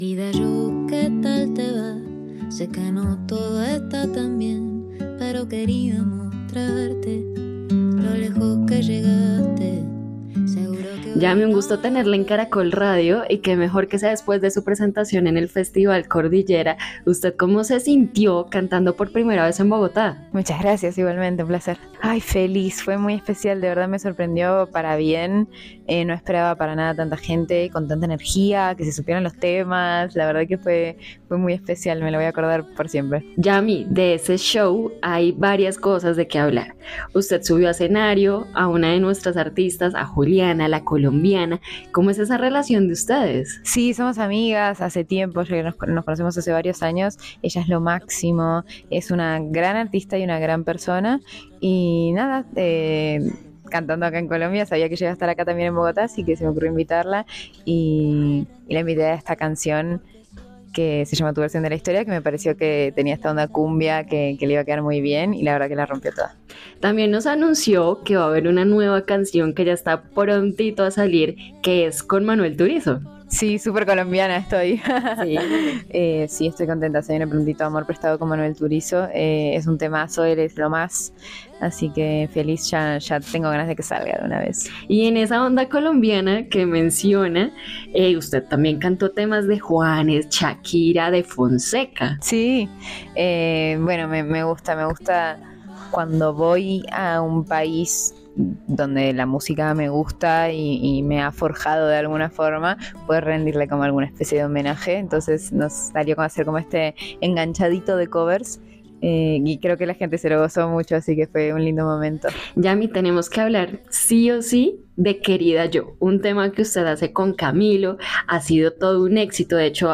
En entrevista con Caracol Radio, Yami Safdie aseguró que se sintió muy feliz y no se esperaba tanta gente: “Fue muy especial, me sorprendió para bien, no me esperaba tanta gente con tanta energía y que se supieran todos los temas, de verdad que fue muy especial y me lo voy a recordar toda la vida” aseguró.